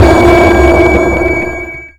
sonarTailWaterCloseShuttle3.ogg